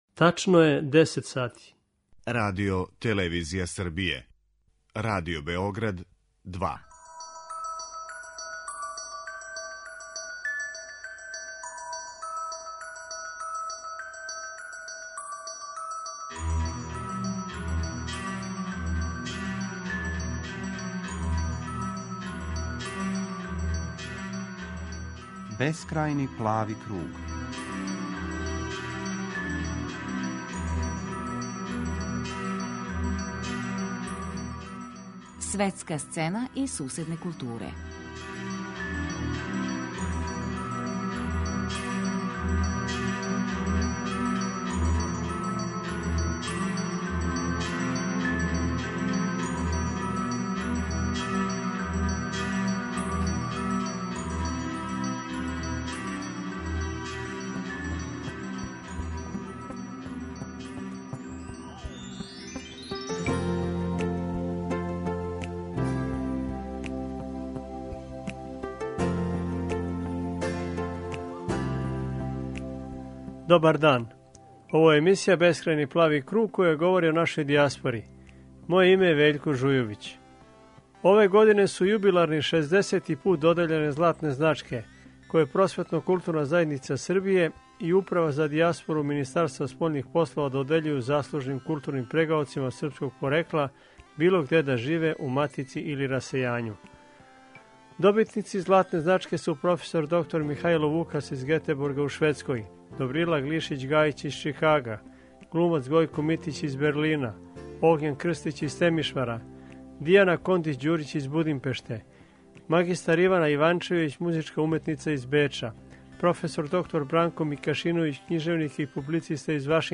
Они ће говорити о свом животу и раду у расејању.